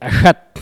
Cri pour chasser le chat ( prononcer le crti )
Catégorie Locution